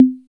50 808 TOM.wav